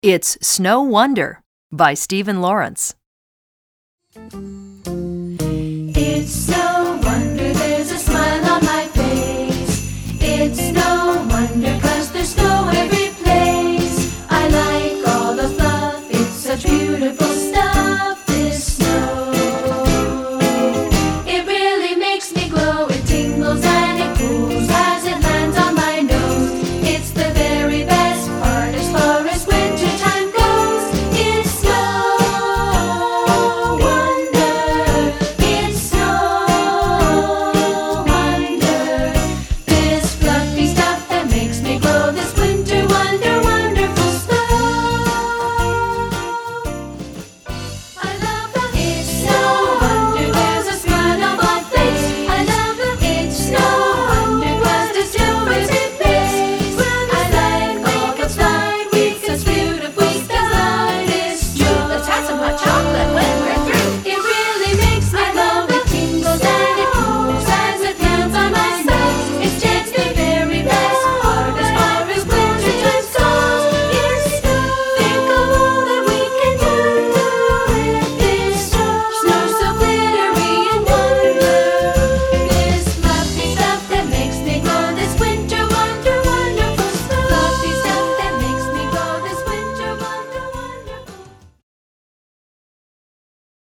Voicing: VoiceTrax